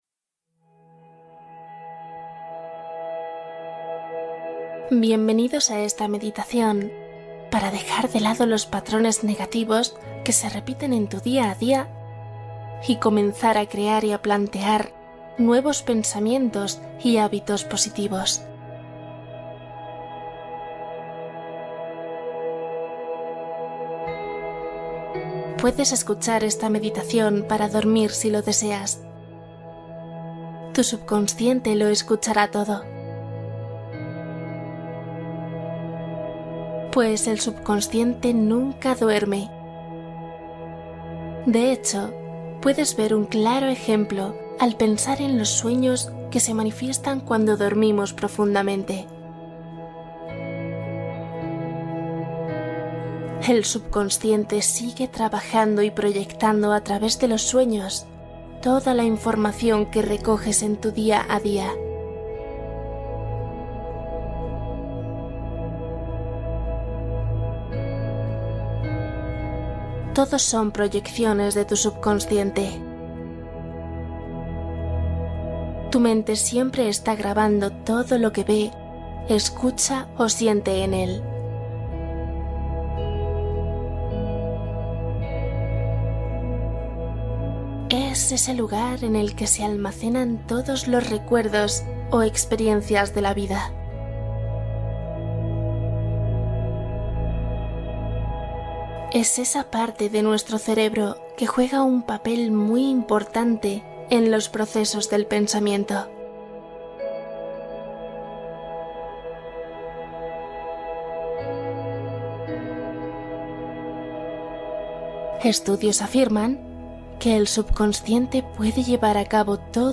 Meditación de 21 días para crear el hábito del éxito